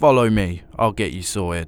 Voice Lines
Follow me ill get you sorted.wav